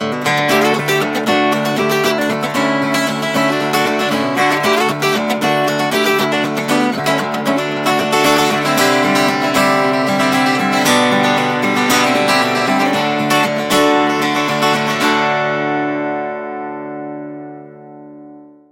Guitar Ringtones